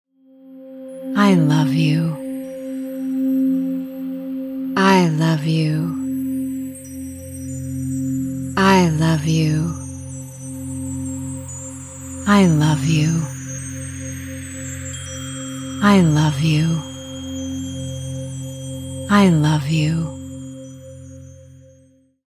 The subtle and powerful movement of sound maximizes the relaxation of the nervous system and the balancing of the right and left hemispheres of the brain.
To optimally experience the rich textures and multidimensional, intentional frequencies of this recording, it is recommended that you wear headphones, or position yourself between two speakers.